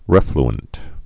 (rĕfl-ənt)